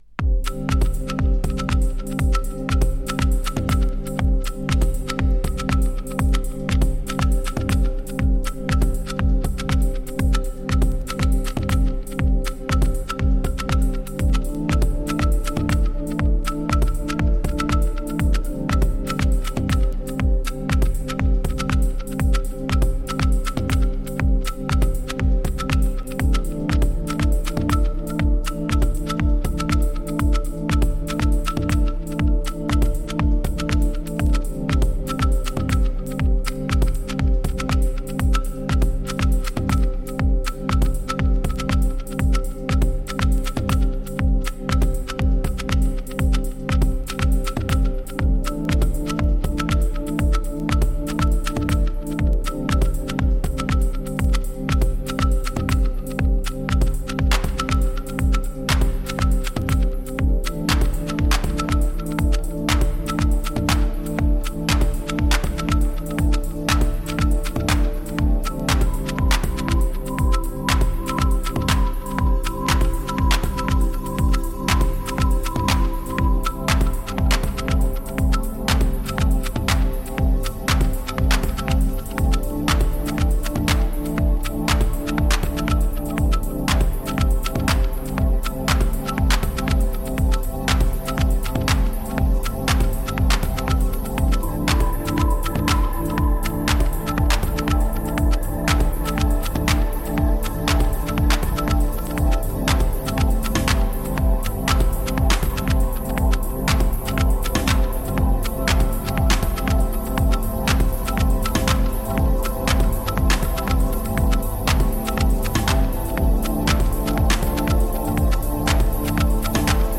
New Release Deep House House